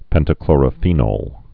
(pĕntə-klôrə-fēnōl, -nôl, -nŏl)